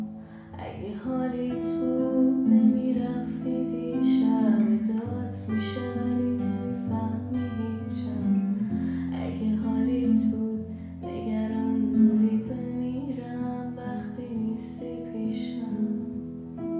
ورژن گیتار